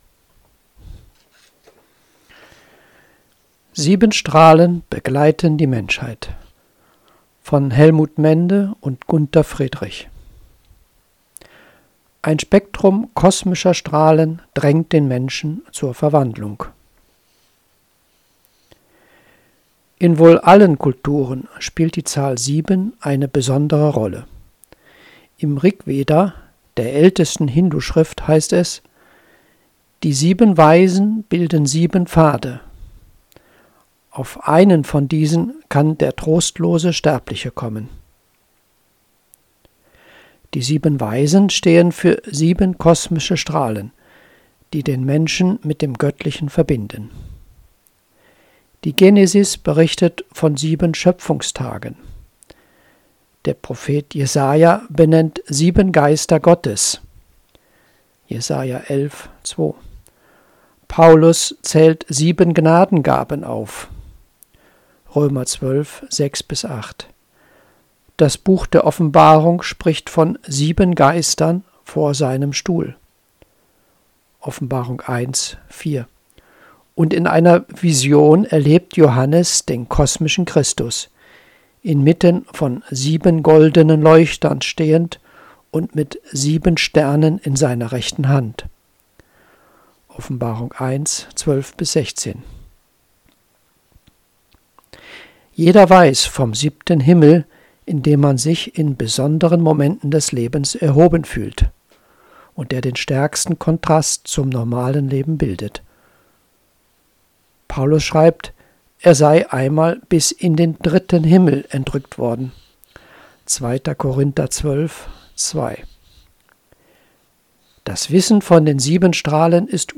LOGON-Artikel gelesen